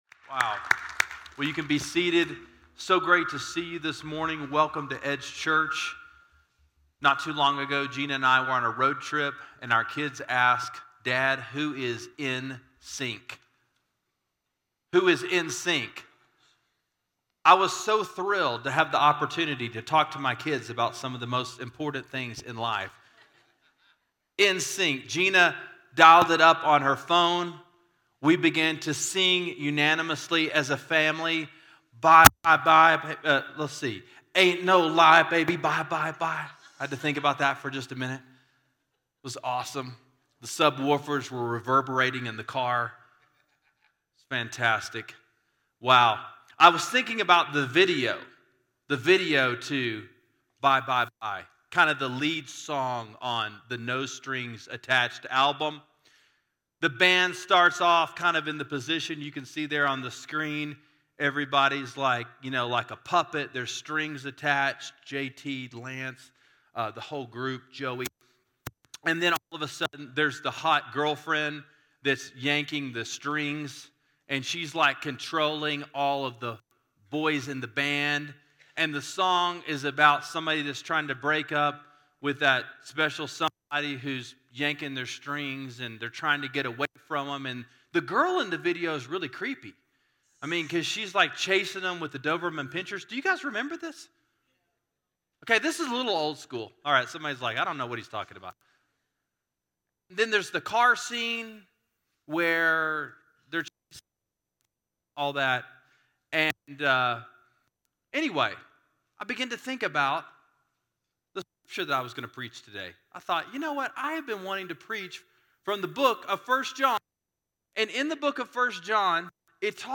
No Strings Attached: Life Giving Love: 1 John 2:1-6 – Sermon Sidekick